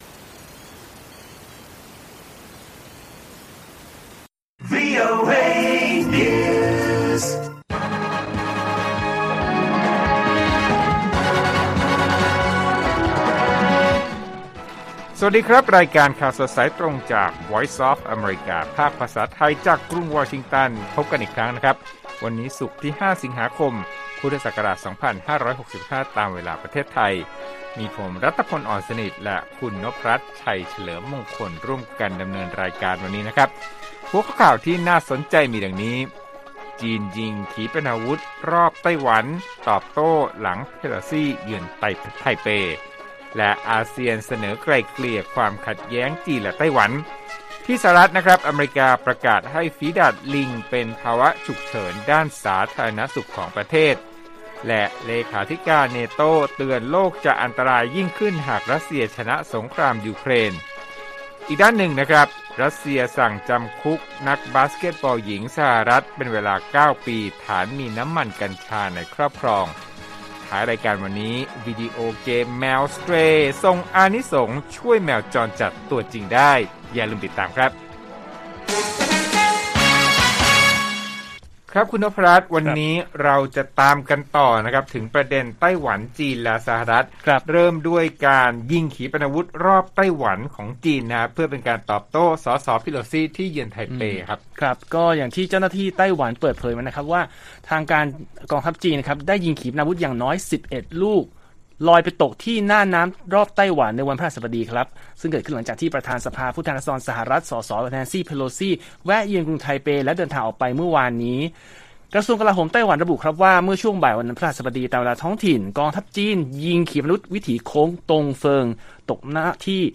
ข่าวสดสายตรงจากวีโอเอไทย 8:30–9:00 น. วันที่ 5 ส.ค. 65